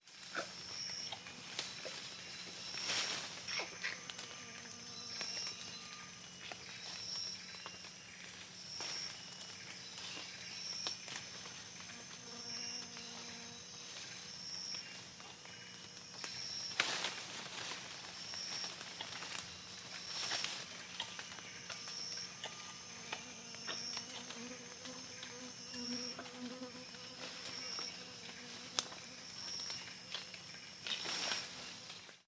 Орангутан лакомится фруктом на ветке